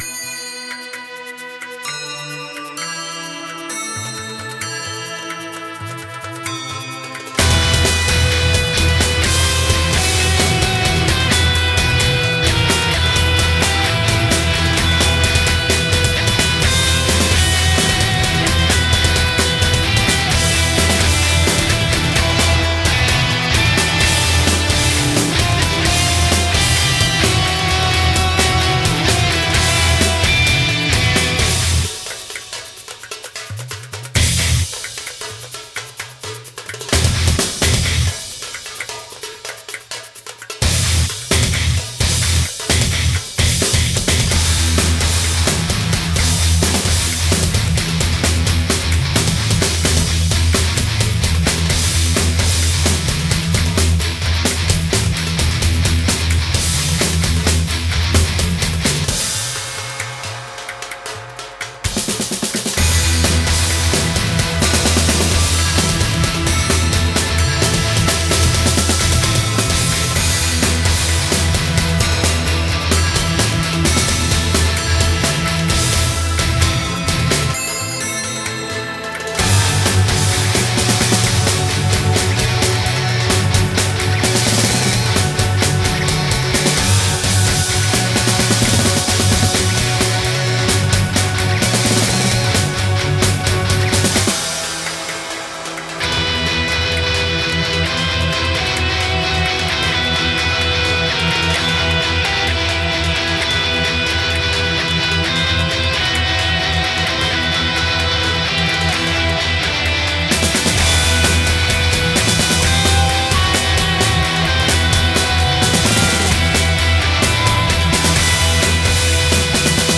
Experimental Metal